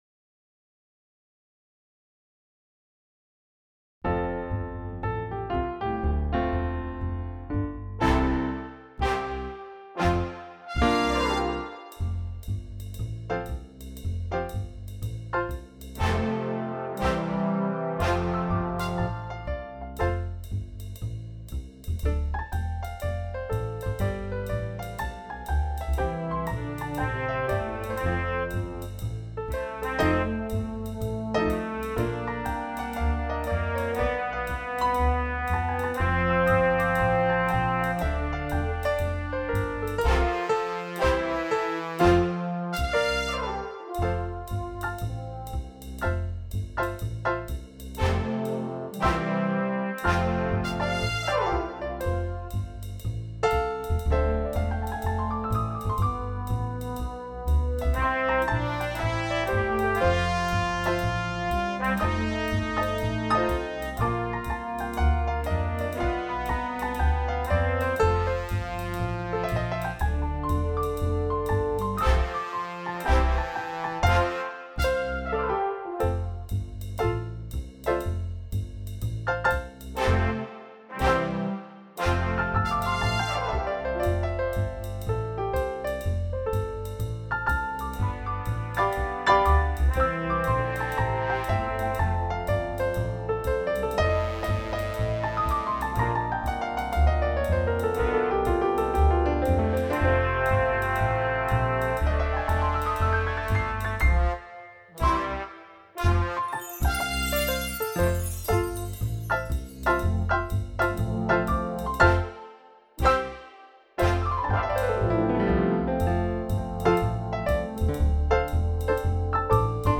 Accompaniment  (Copyright)